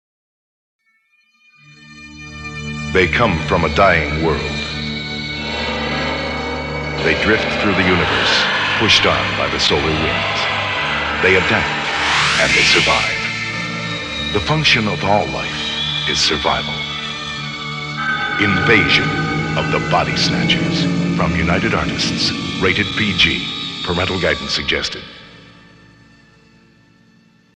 Radio Spots
The stereo spots are basically the same with minor differences, but the mixing is a little sharper and the background effects are more pronounced.